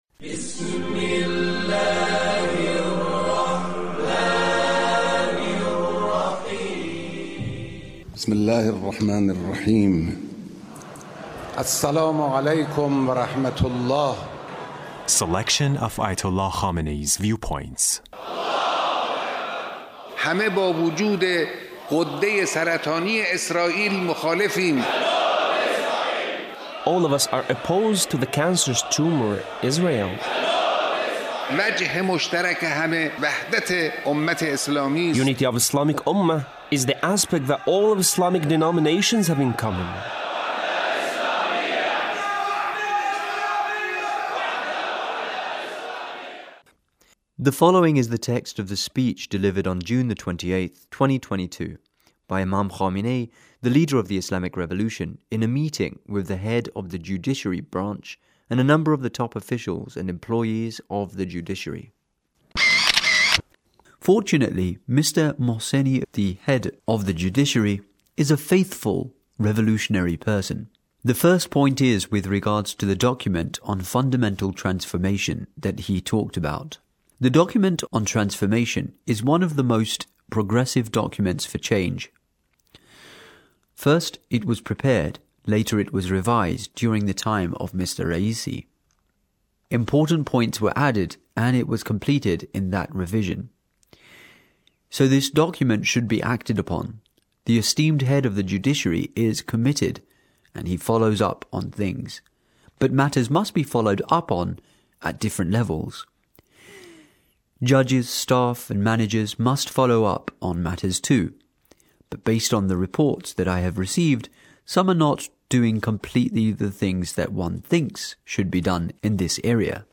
he Leader's speech in a meeting with a number of the top officials and employees of the Judiciary.